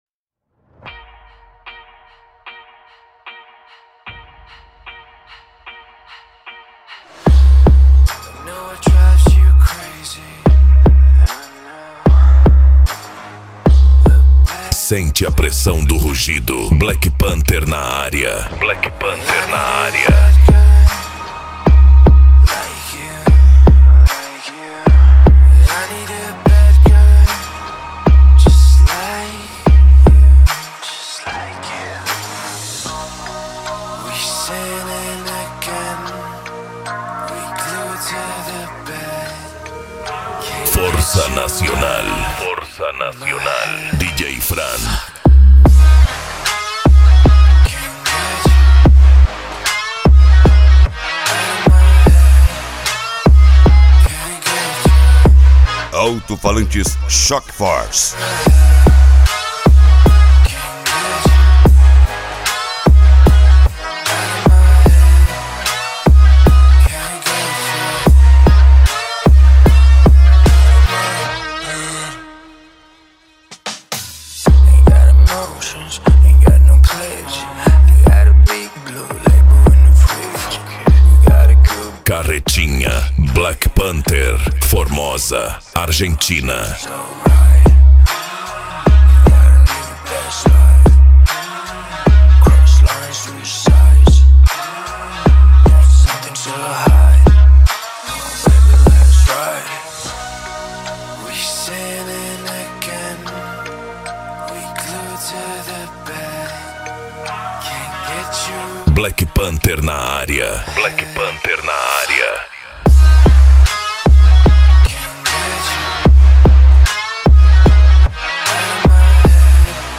Bass
Funk
Psy Trance
Remix